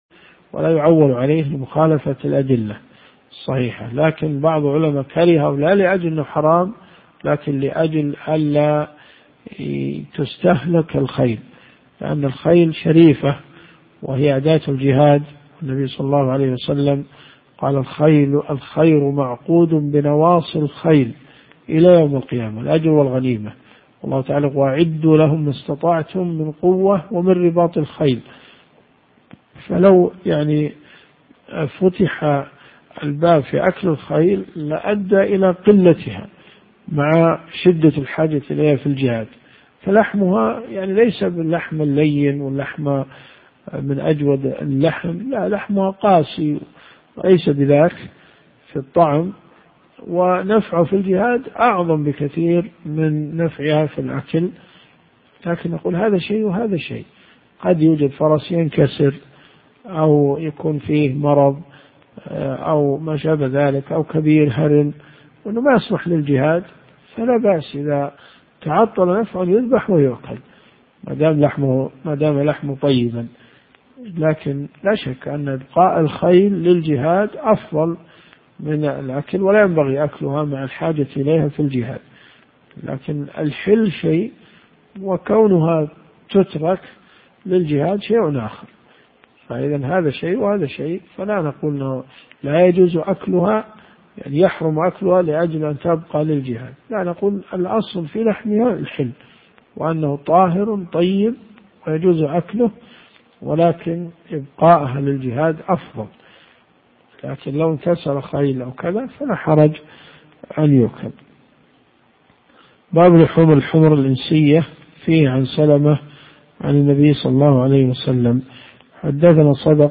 الرئيسية الكتب المسموعة [ قسم الحديث ] > صحيح البخاري .